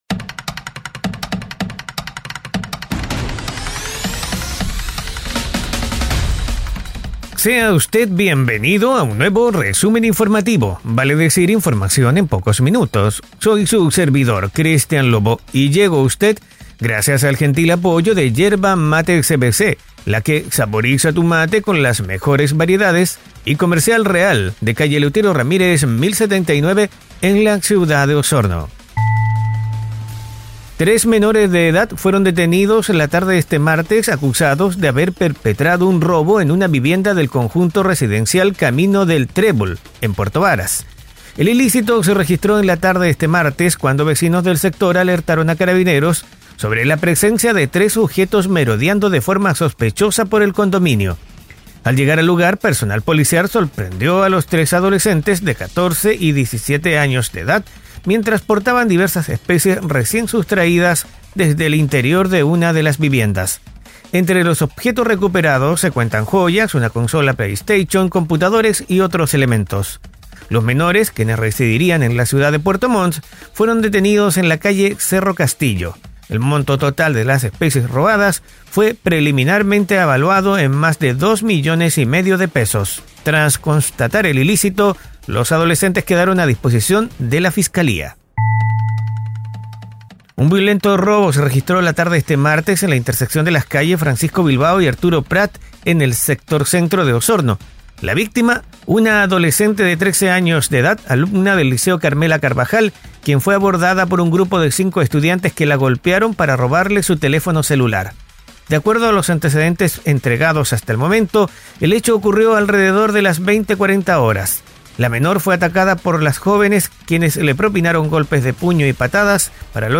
Este audio podcast te trae un resumen rápido y conciso de una decena de noticias enfocadas en la Región de Los Lagos.